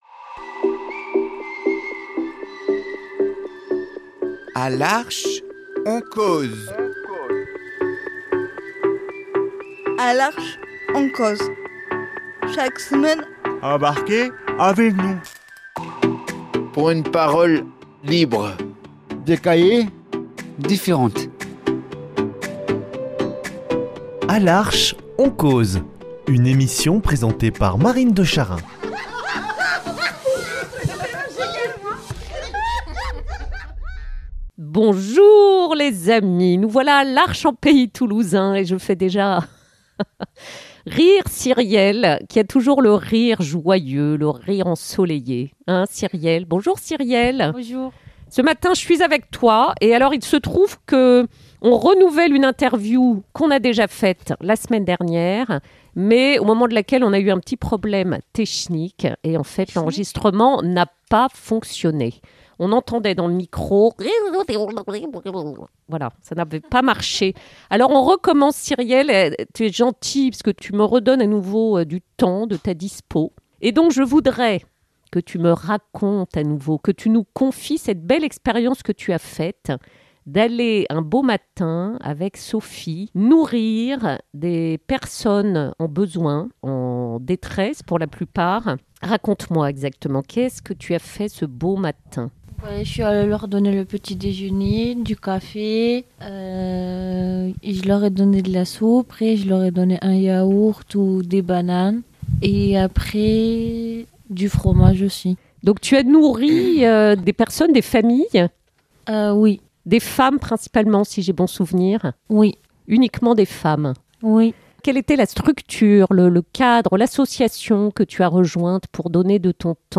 Un témoignage de générosité tout en humilité et en émotions !